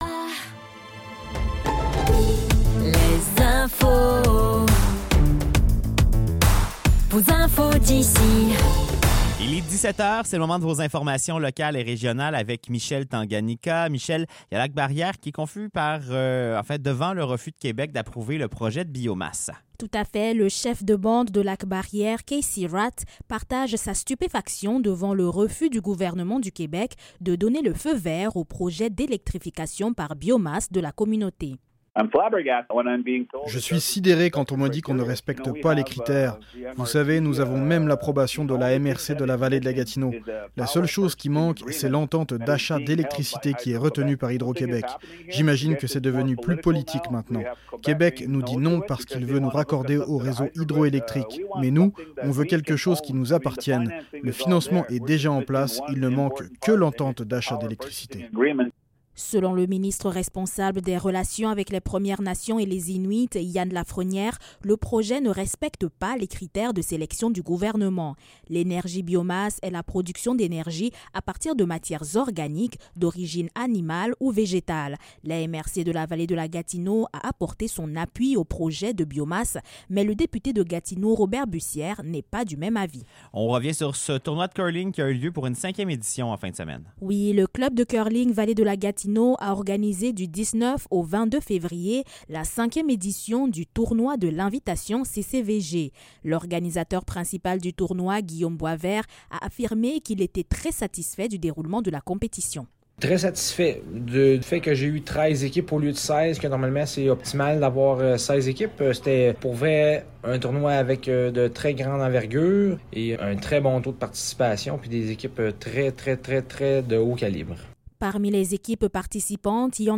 Nouvelles locales - 23 février 2026 - 17 h